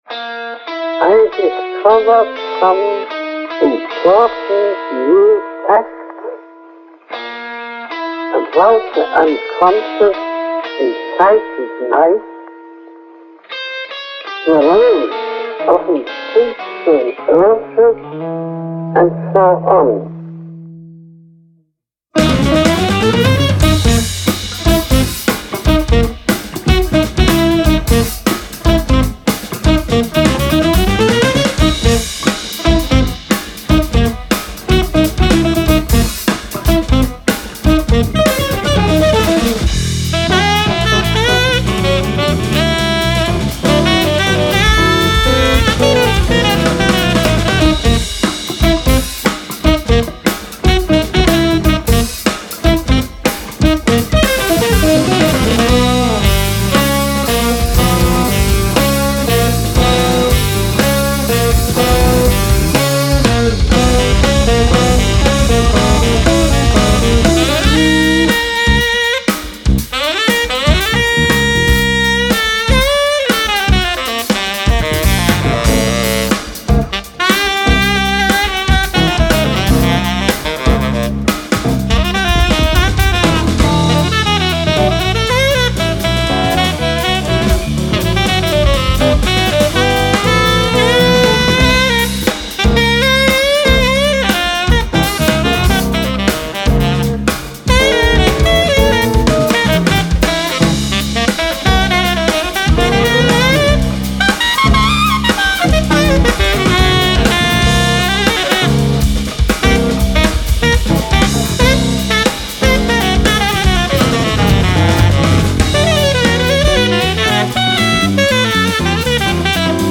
Genere: Fusion.